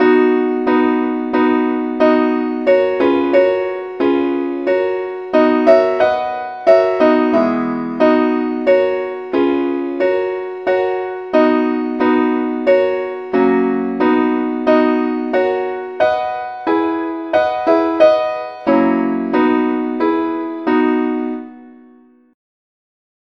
Meter: 6.6.8.6